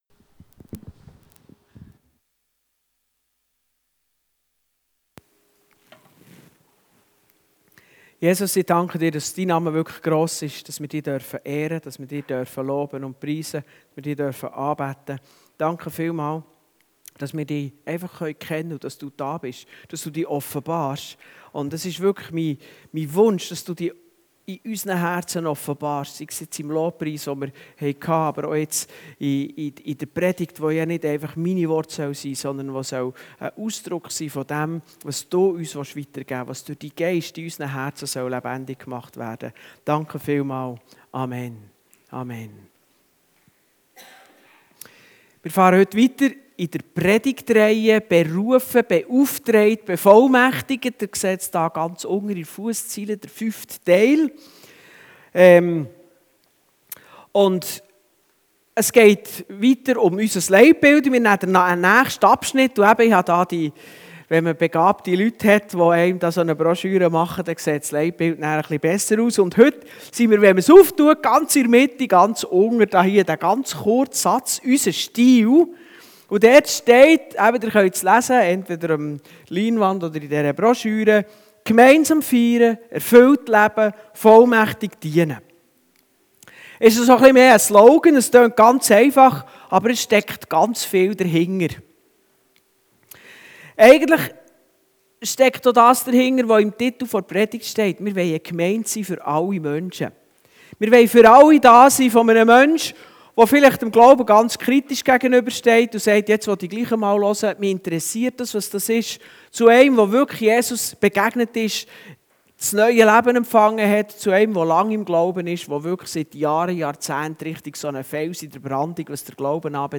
Johannes 2, 12-14 Dienstart: Gottesdienst Bible Text: 1.